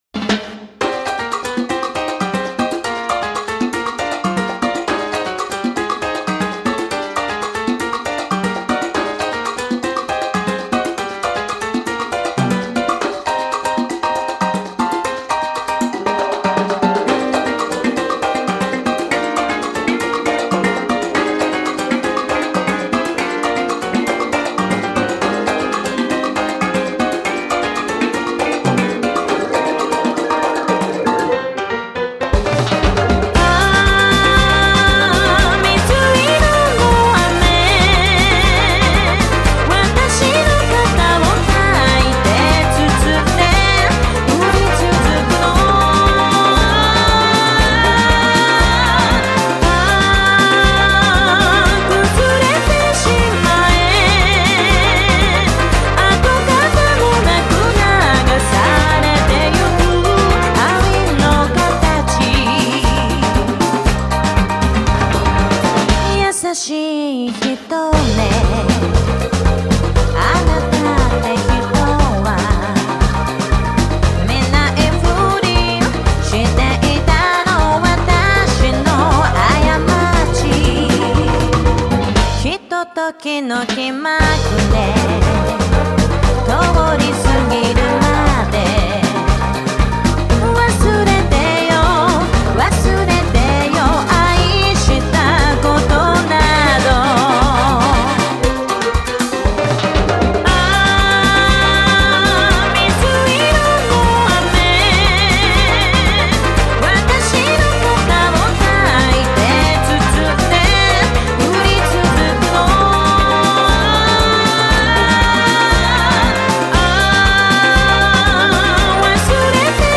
ジャンル(スタイル) JAPANESE POP / LATIN / DISCO